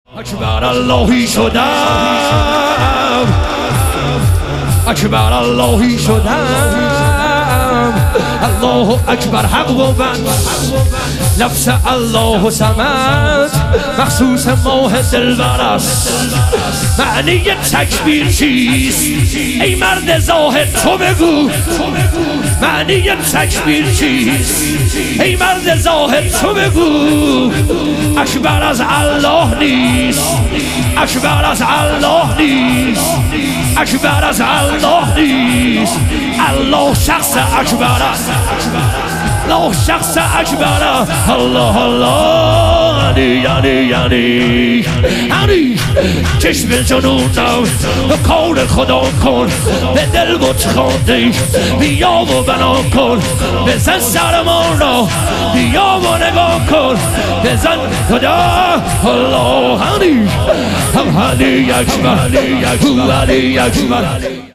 لیالی قدر و شهادت امیرالمومنین علیه السلام - شور